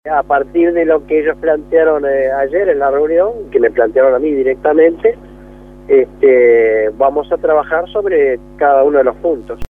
En conversación con El Espectador, Mirza agregó que de aquí al sábado seguirán trabajando y aseguró que se van a producir nuevos encuentros